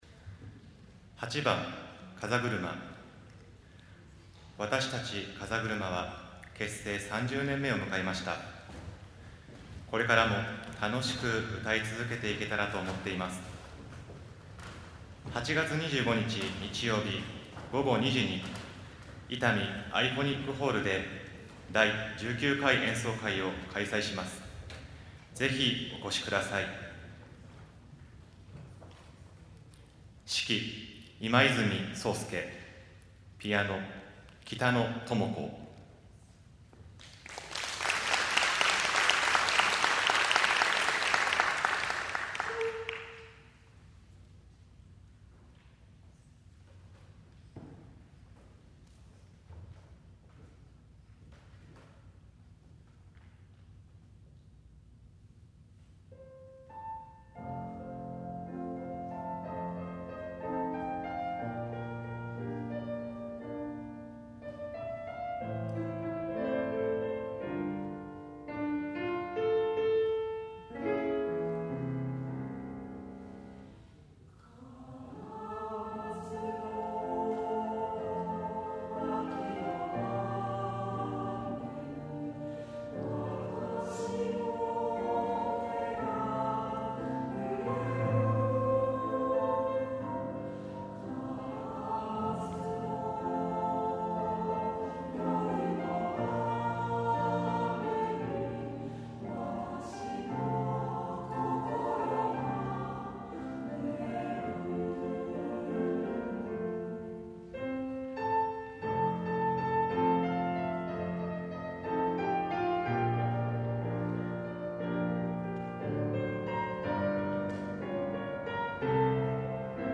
61th_osaka_gashousai.mp3